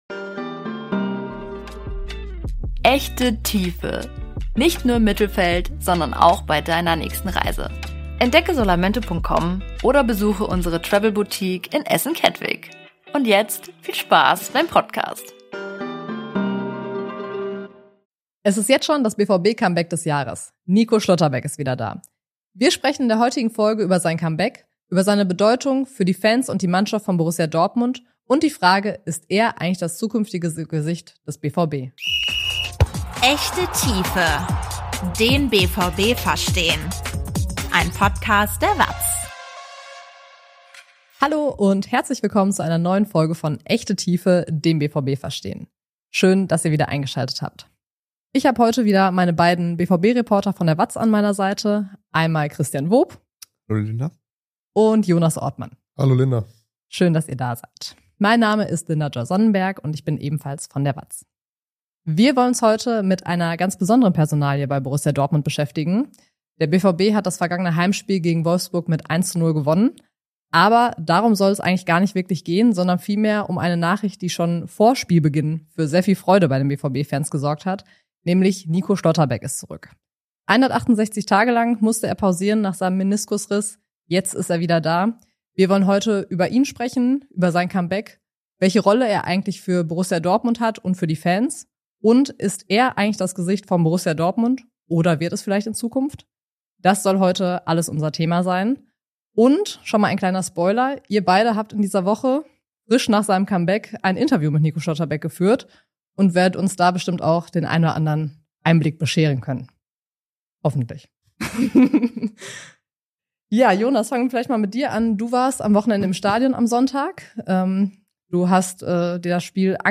BVB-Talk
BVB-Reporter diskutieren ein Thema in der Tiefe